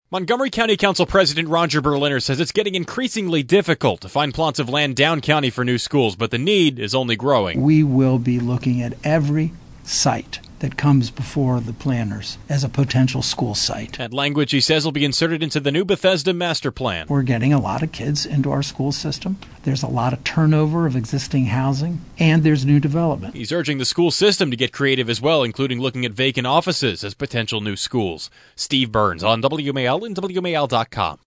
As the County Council puts the finishing touches on an updated Bethesda Master Plan, Montgomery County Council President Roger Berliner advocated for the inclusion of language promising a new solution to the lingering problem.